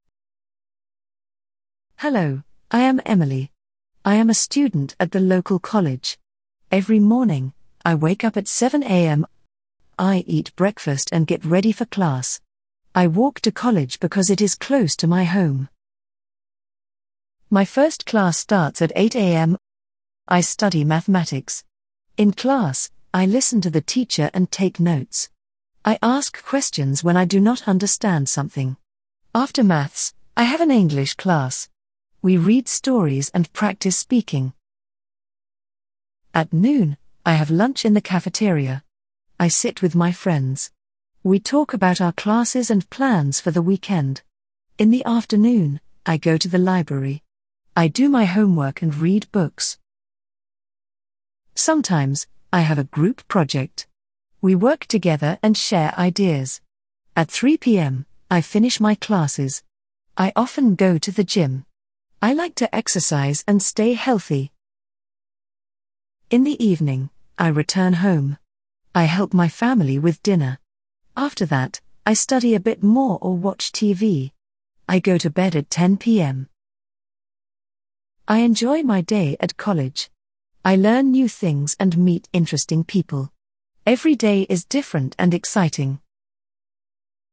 Beginner Listening Practice
A student describes her typical daily routine at college, from classes to activities.